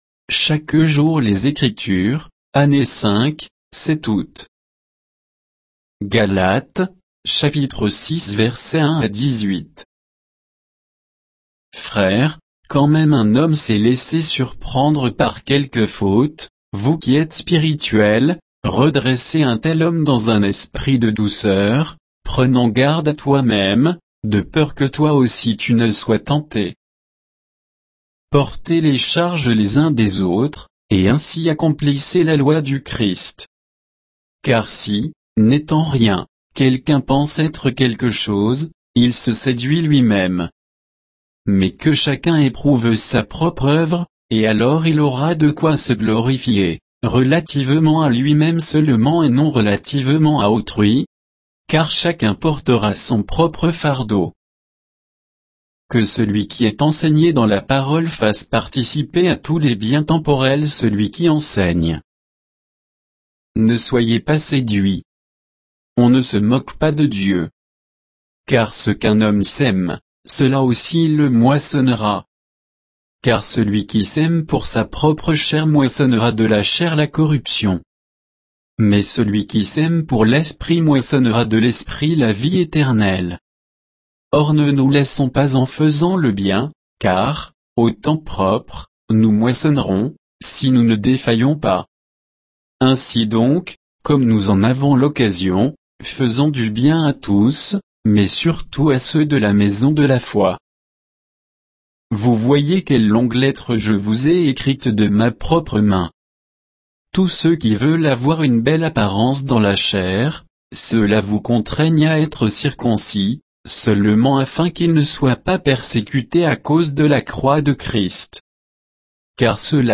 Méditation quoditienne de Chaque jour les Écritures sur Galates 6, 1 à 18